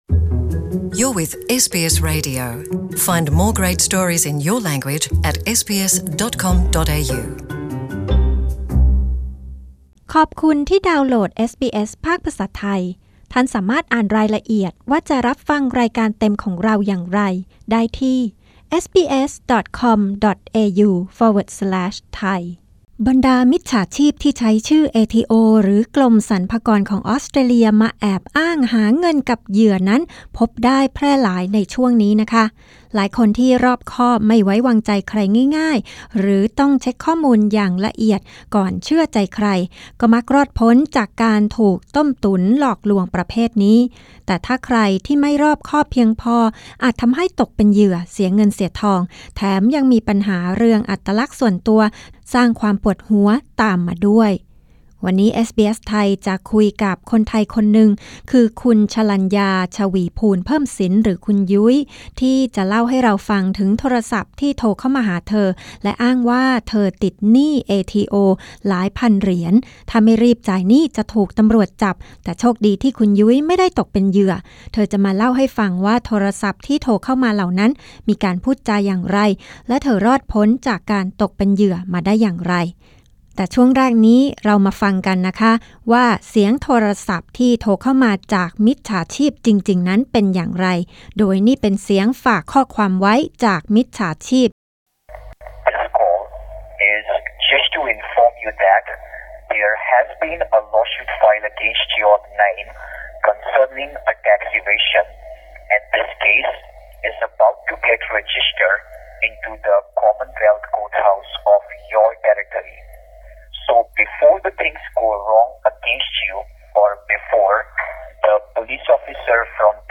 เรามีบทสัมภาษณ์ พร้อมตัวอย่างเสียงโทรศัพท์หลอกลวงหนี้ภาษี และคำแนะนำจากเจ้าหน้าที่คนไทยของเอทีโอ